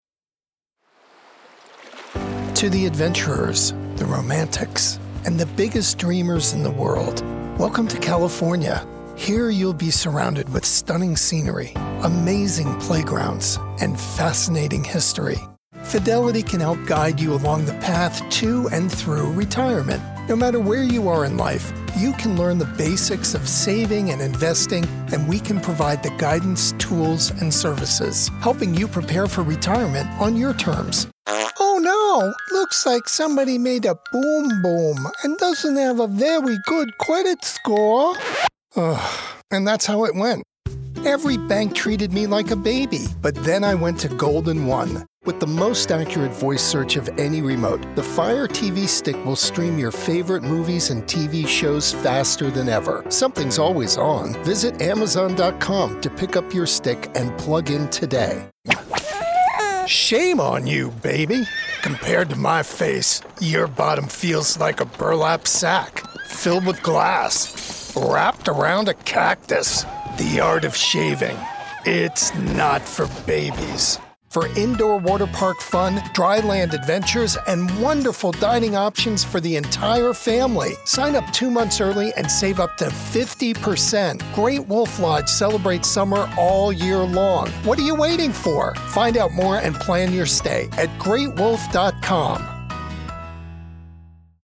Voice Over Commercial
Deep voice with very good pitch control. Able to perform multiple English accents.
I have a deep, persuasive and memorable voice.